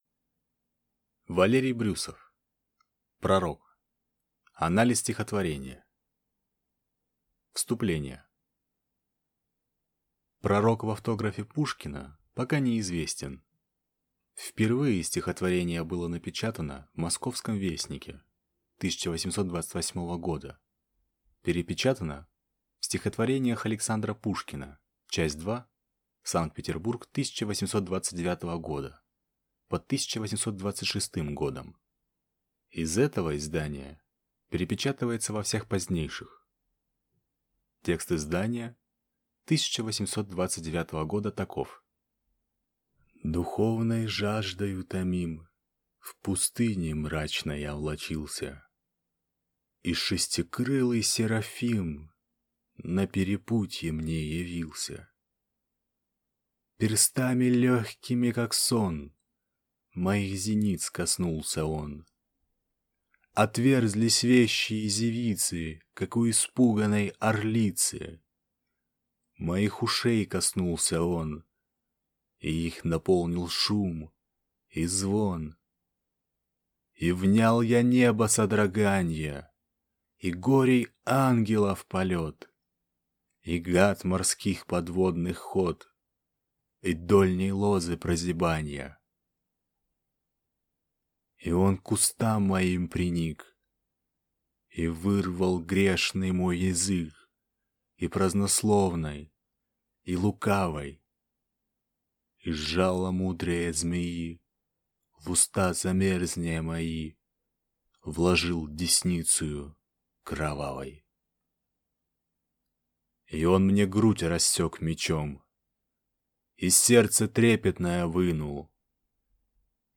Аудиокнига «Пророк». Анализ стихотворения | Библиотека аудиокниг